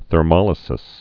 (thər-mŏlĭ-sĭs)